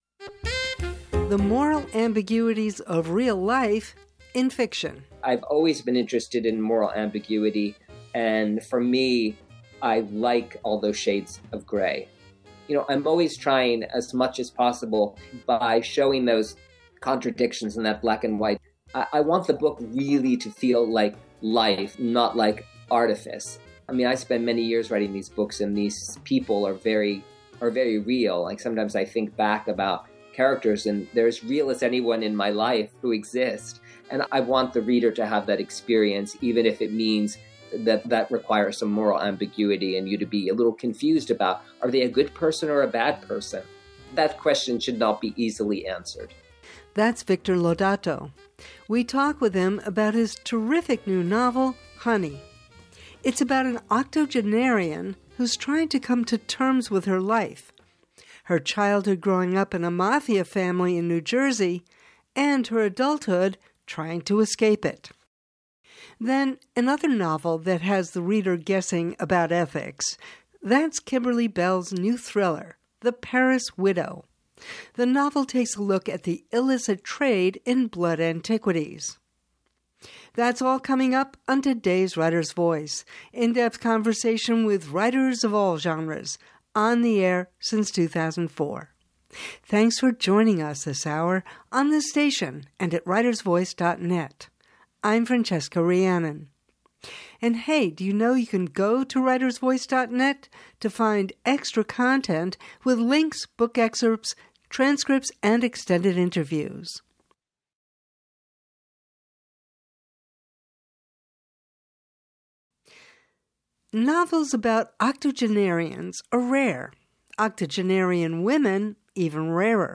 author interview